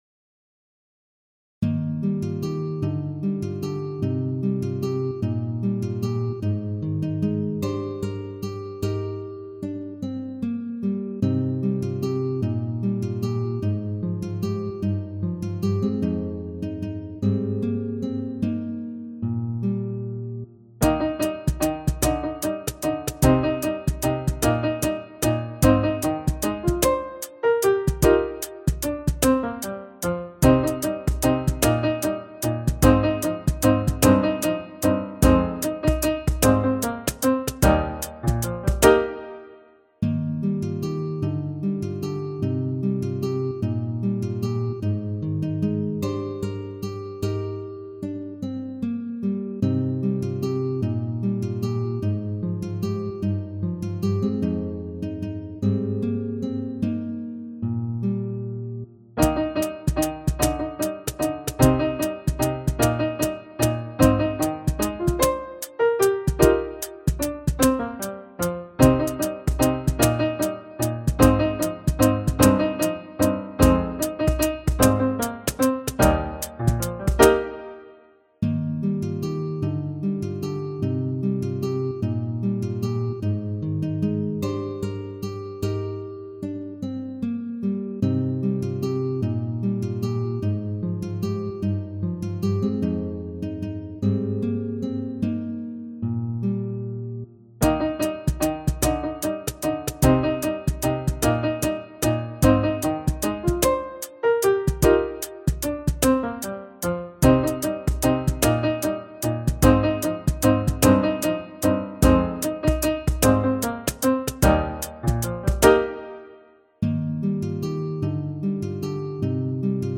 קבצי שמע לתרגול (שירי נגינה עליזים ומלאי מוטיבציה) –
Track-7-Ocean-drum-new.mp3